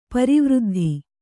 ♪ pari vřddhi